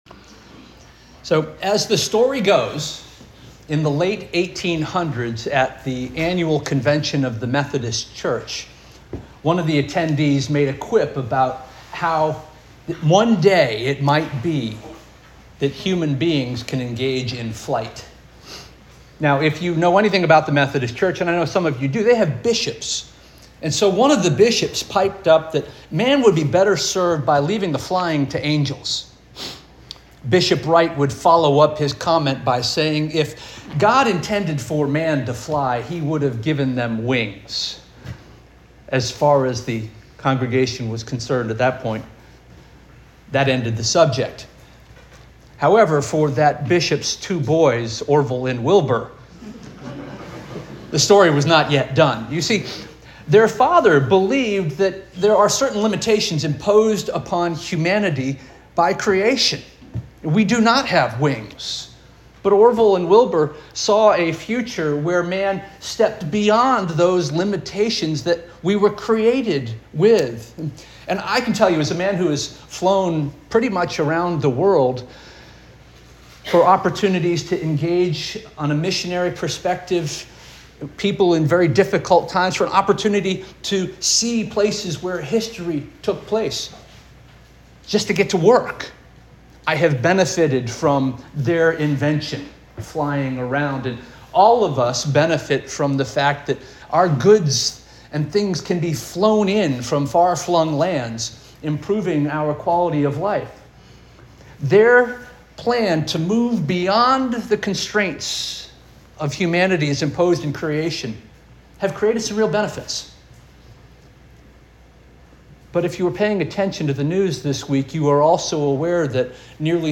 June 15 2025 Sermon - First Union African Baptist Church